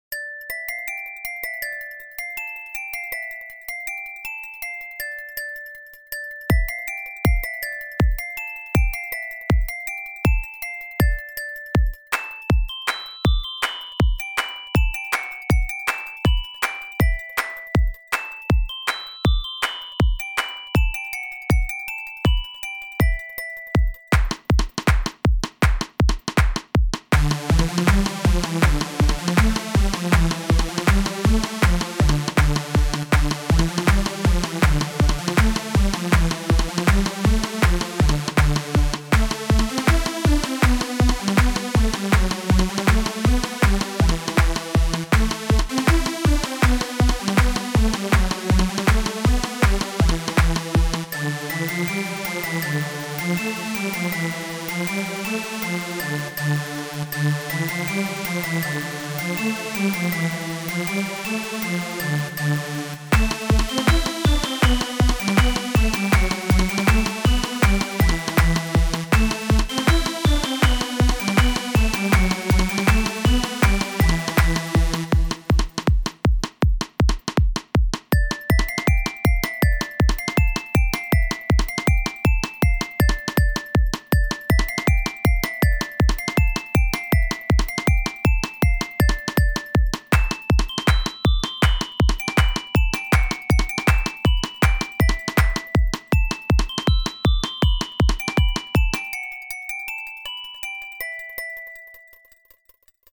thats a pretty Sick beat bro